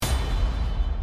reze boom Meme Sound Effect
Category: Anime Soundboard
reze boom.mp3